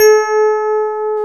Index of /90_sSampleCDs/Roland - Rhythm Section/KEY_Pop Pianos 4/KEY_Rhodes + EP